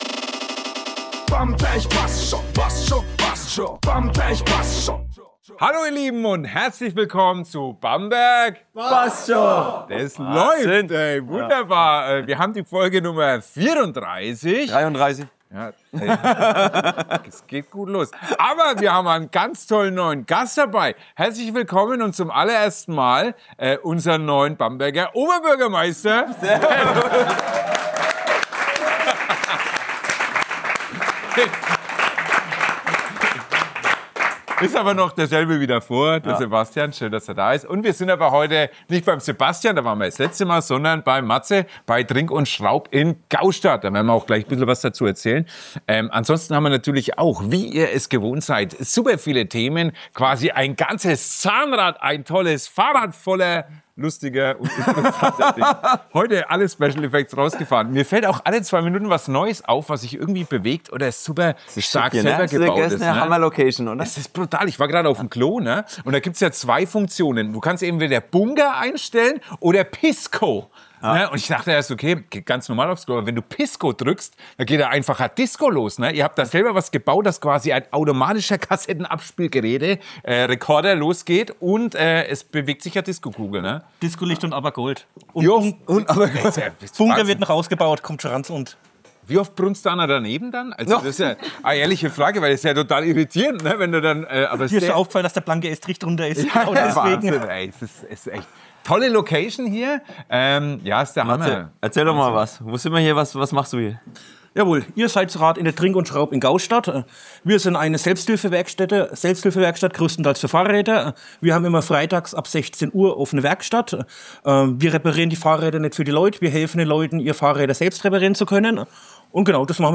Beschreibung vor 1 Tag In dieser Folge von „Bamberg bassd scho!“ sind wir zu Gast bei „Trink & Schraub“ in Gaustadt – einer besonderen Fahrrad-Selbsthilfewerkstatt, die für Gemeinschaft, Kreativität und echtes Miteinander steht.